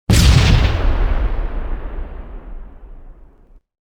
OtherHit3.wav